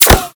/ gamedata / sounds / material / bullet / collide / default04gr.ogg 9.4 KiB (Stored with Git LFS) Raw History